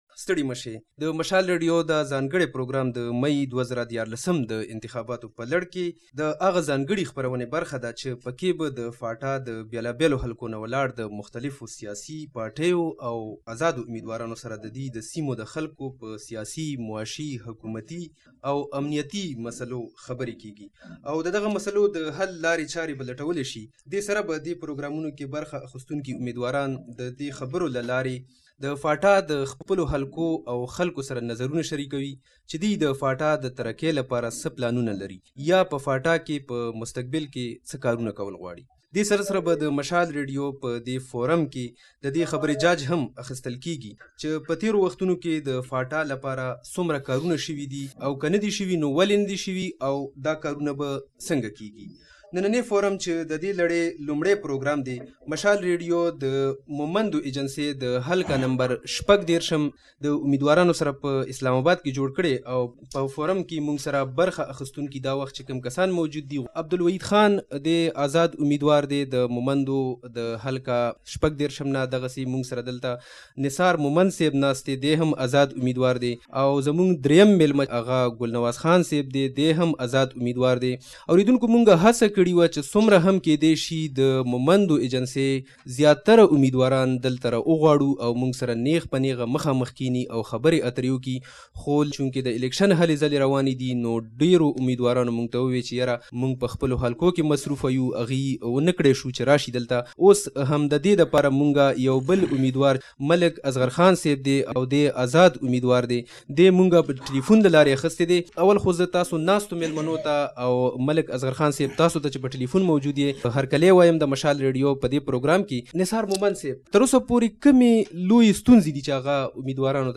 قبایلي سیمې او ټولټاکنې: مومند اېجنسۍ ګړدۍ مېز